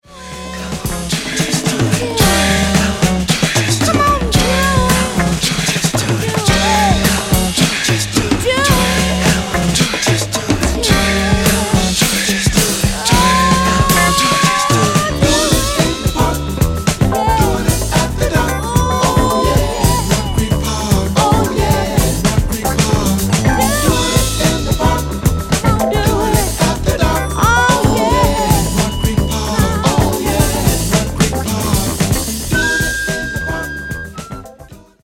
La Collana contiene in ogni volume Rare Versioni Remix